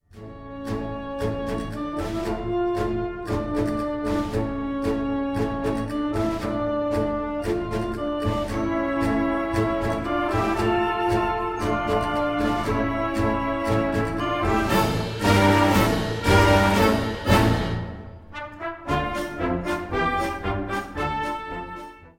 Besetzung: Blasorchester
Full of fire and energy!